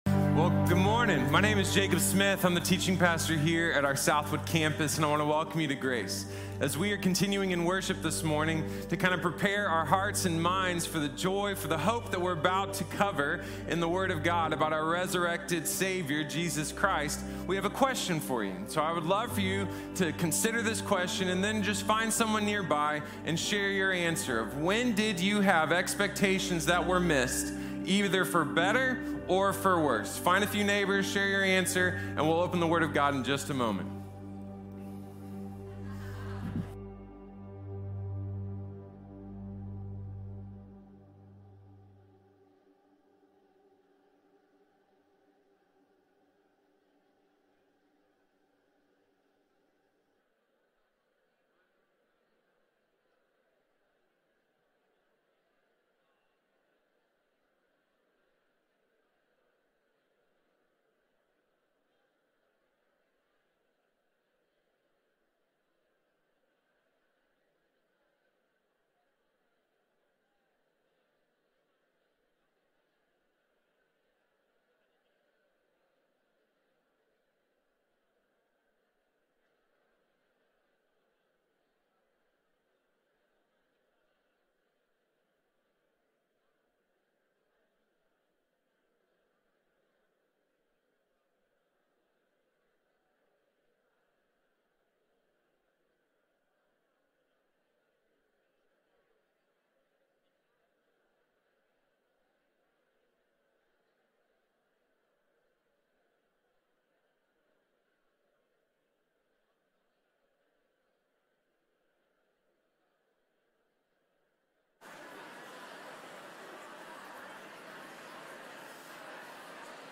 Easter Sunday | Sermon | Grace Bible Church